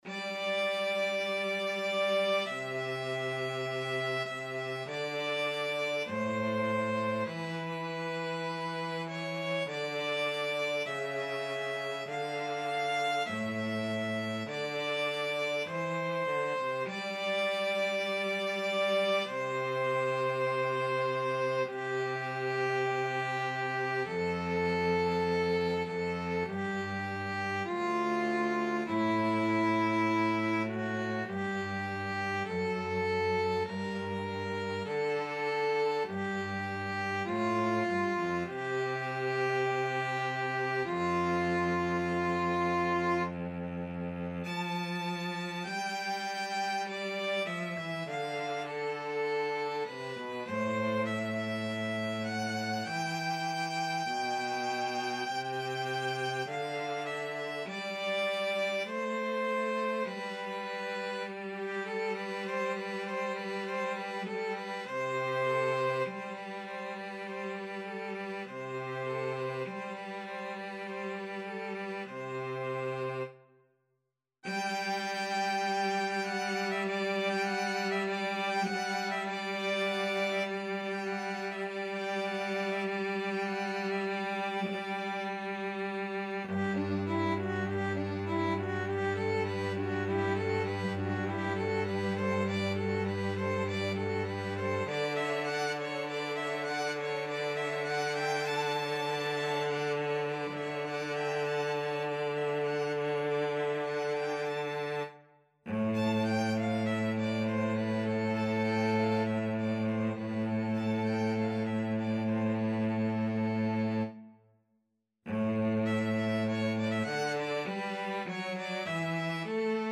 Violin 1Violin 2Violin 3Cello
4/4 (View more 4/4 Music)
Classical (View more Classical String Quartet Music)